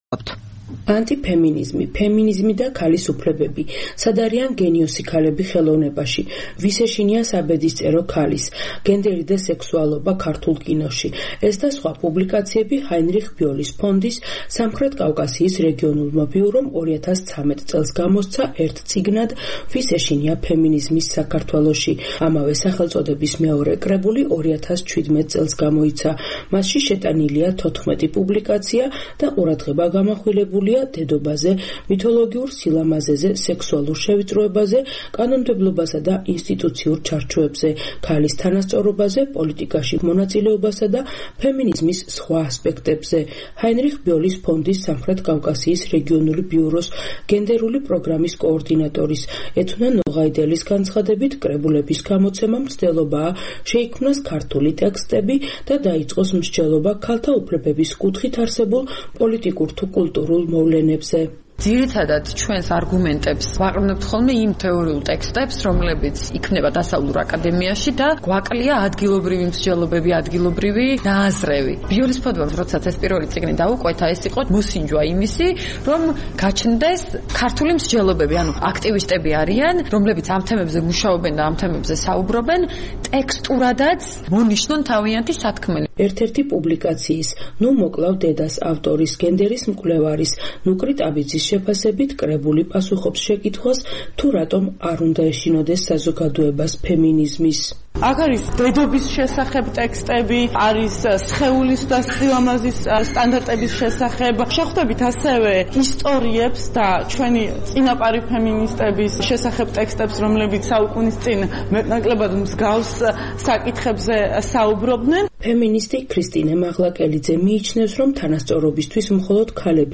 წიგნის პრეზენტაცია და დისკუსია ქუთაისში